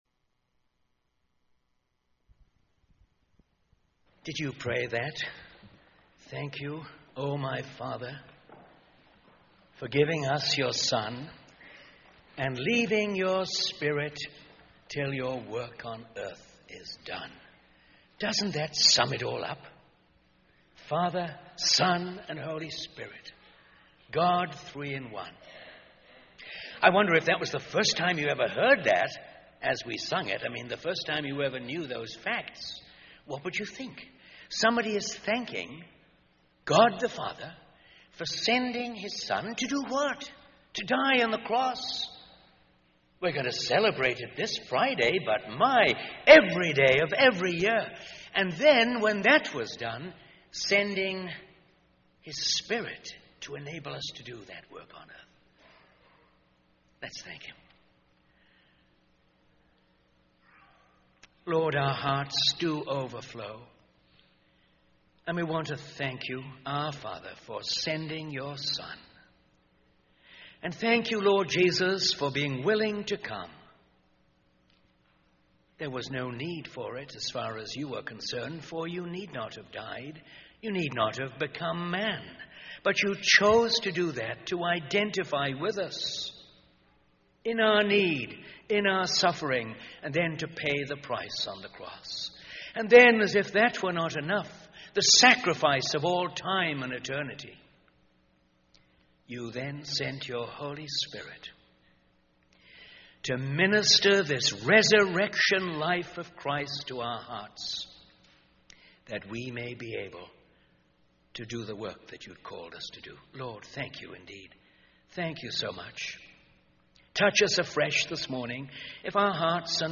In this sermon, the speaker discusses the emotional and spiritual anguish that Jesus experienced before his crucifixion. The speaker emphasizes that Jesus had not yet been physically harmed, but his soul was overwhelmed with sorrow to the point of death. The sermon focuses on seven specific words that describe Jesus' anguish, which are found in the Bible.